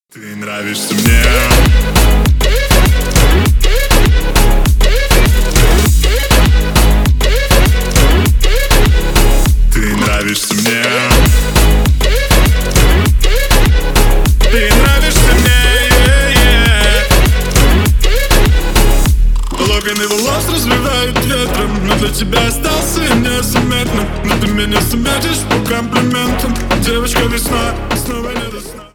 Поп Музыка
клубные # громкие